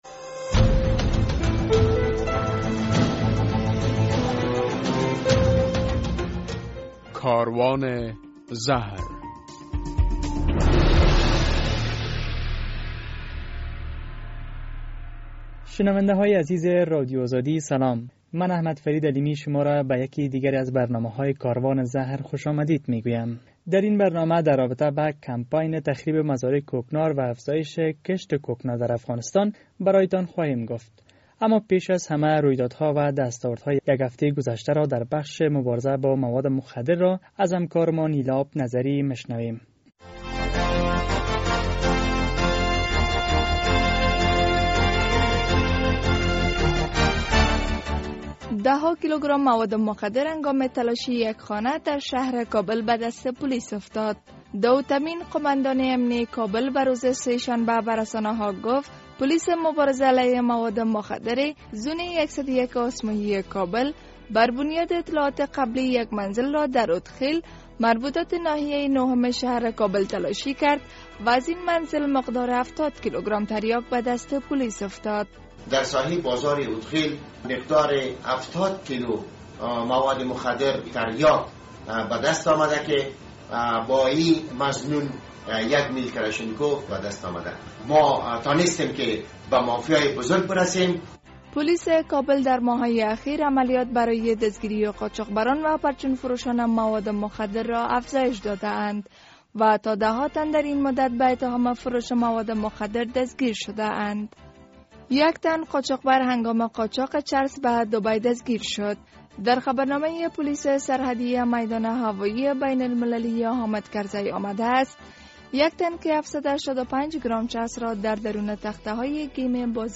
در برنامه این هفته کاروان زهر نگاه زود گذر داریم به رویدادهای مهم در بخش مواد مخدر، در گزارشی می‌شنوید که بررسی‌های ابتدایی دولت افغانستان نشان می‌دهند ...